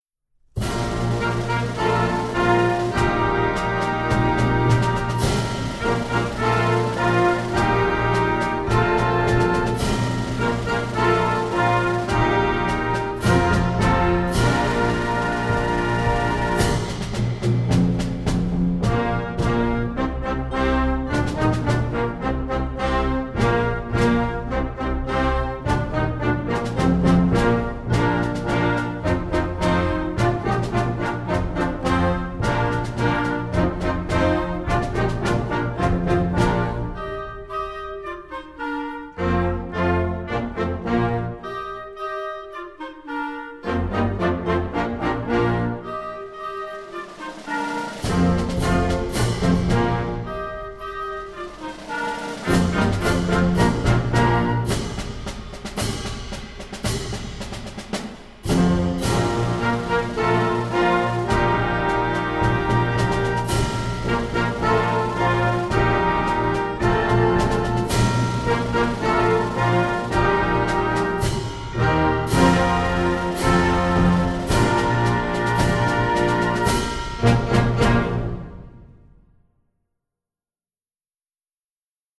Gattung: Jugendwerk für Flexible Band/String Ensemble
Besetzung: Blasorchester